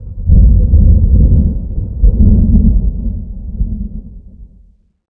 thunder2.ogg